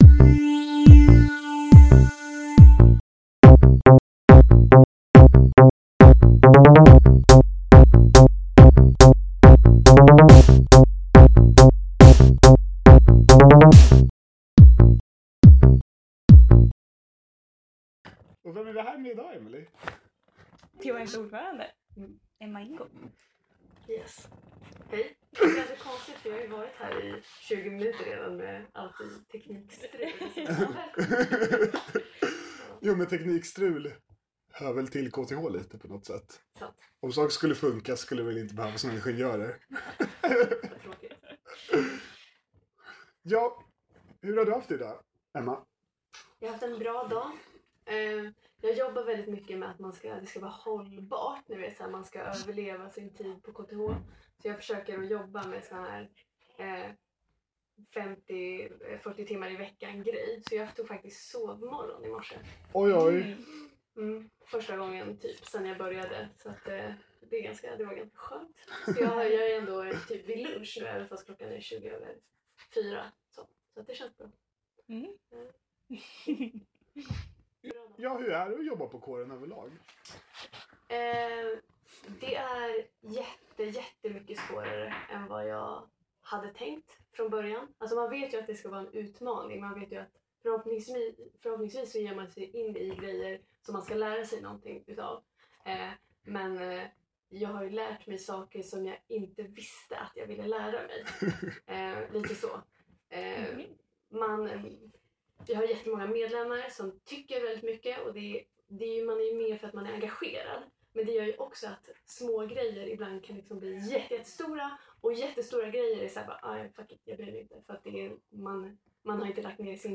intervju-med-ordfrande.2.mp3